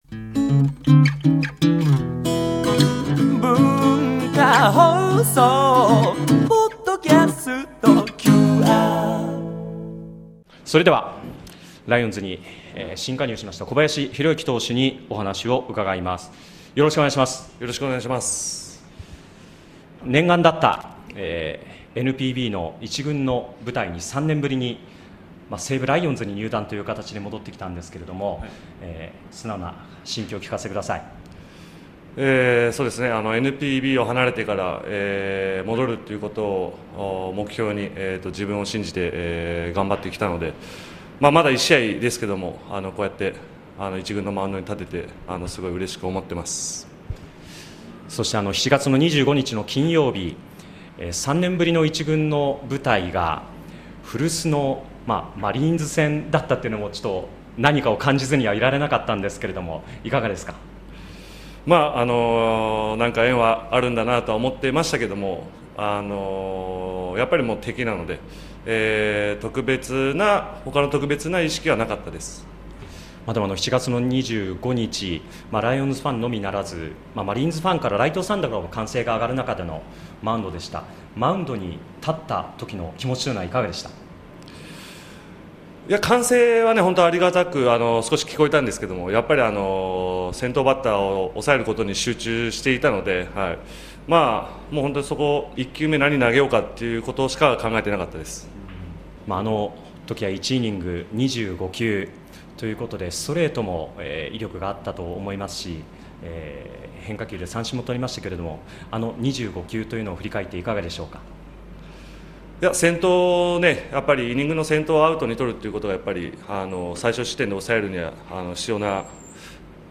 溌剌とした声をお聴き下さい。
小林宏之投手インタビュー